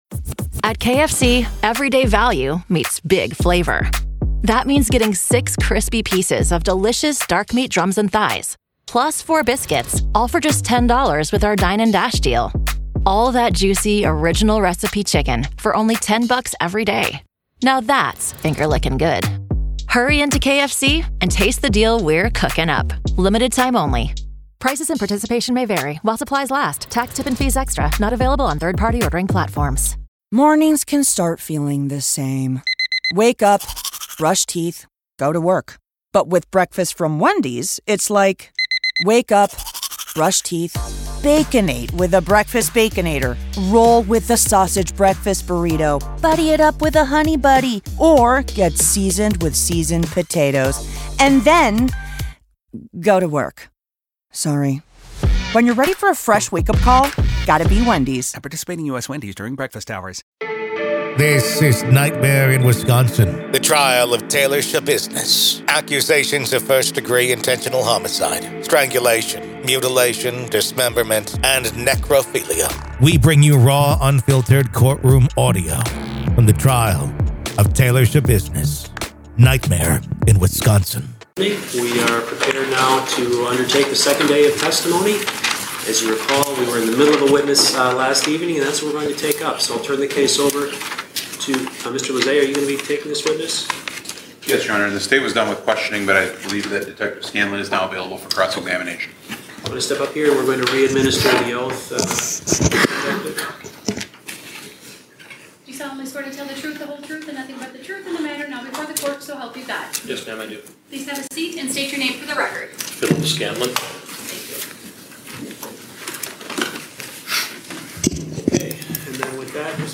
Raw courtroom audio from the hearing and trial